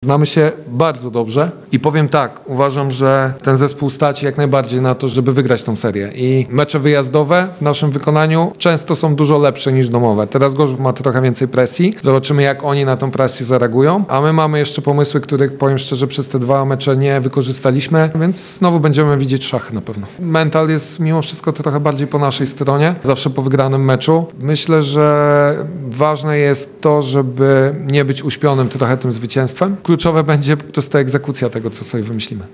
– Znamy się bardzo dobrze i powiem tak, że ten zespół stać na to, żeby wygrać tę serię – mówi w rozmowie z Radiem Lublin.